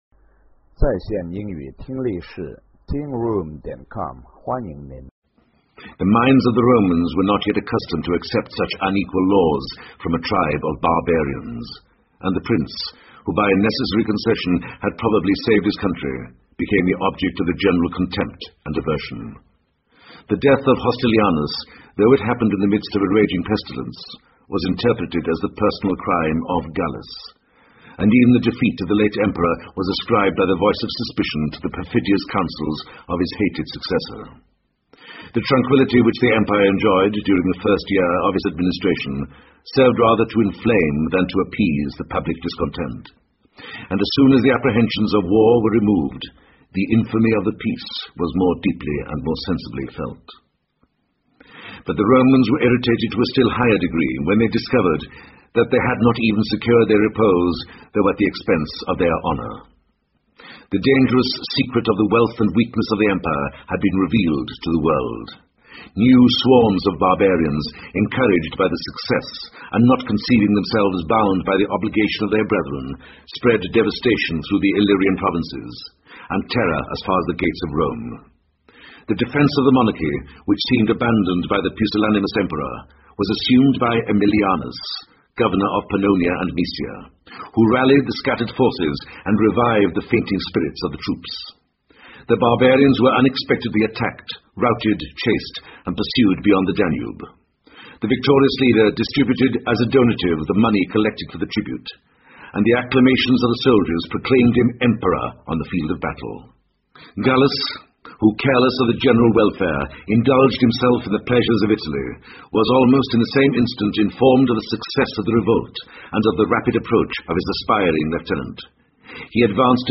在线英语听力室罗马帝国衰亡史第一部分：29的听力文件下载,有声畅销书：罗马帝国衰亡史-在线英语听力室